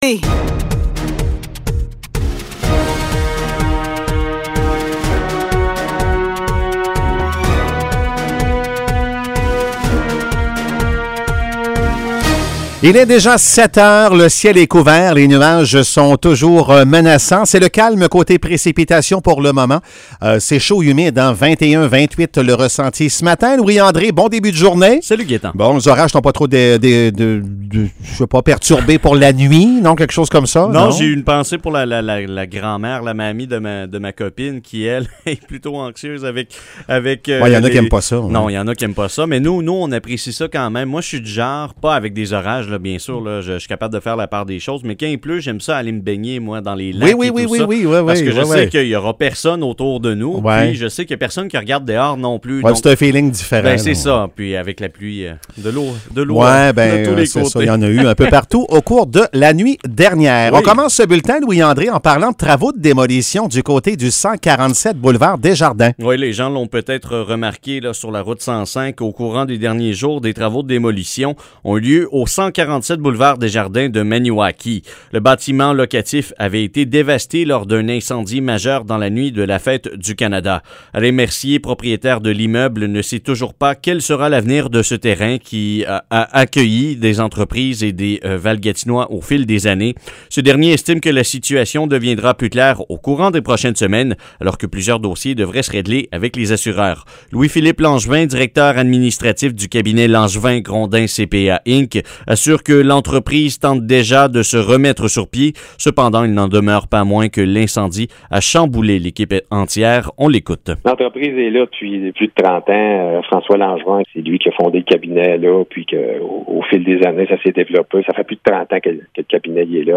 Nouvelles locales - 14 juillet 2021 - 7 h